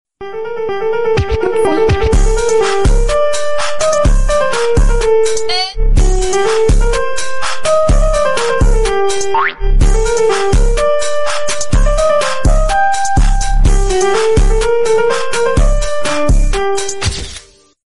hoodtrap